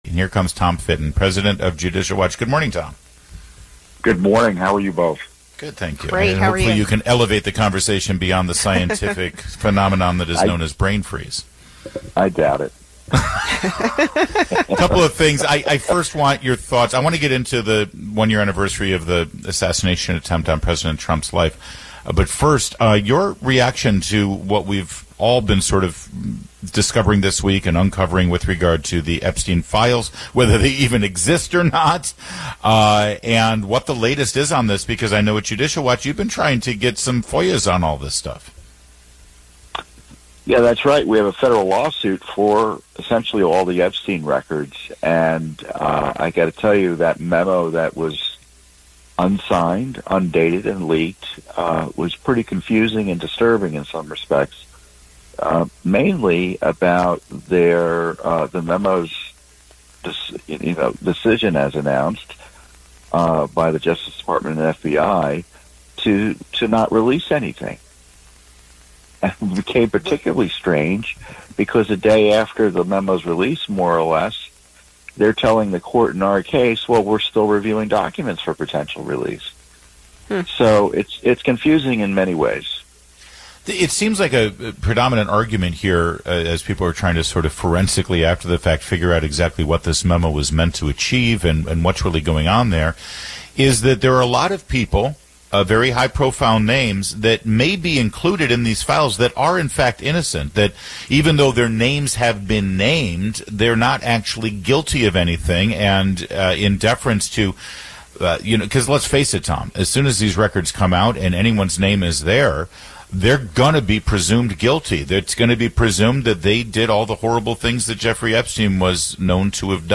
➡ Tom Fitton, President of Judicial Watch, discussed two main issues: the Epstein files and the assassination attempt on President Trump. He expressed concern about the lack of transparency and release of information in both cases.